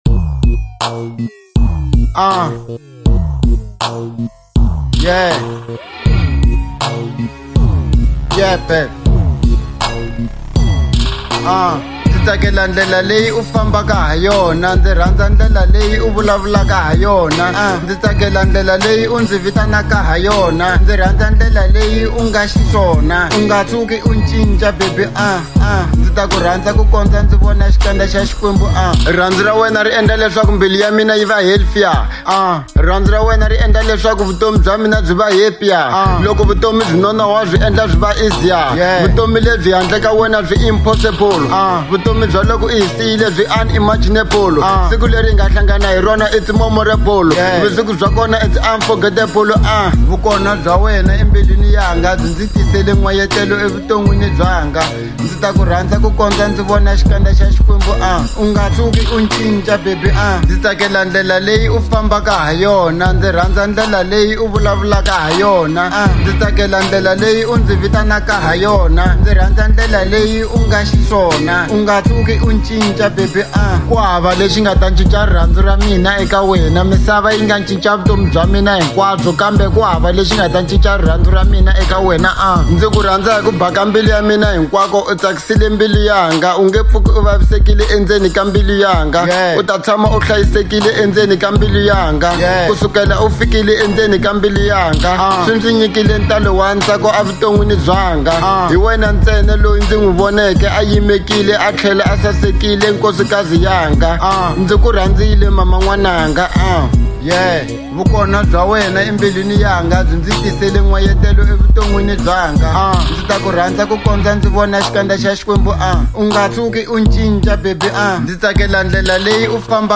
02:23 Genre : Hip Hop Size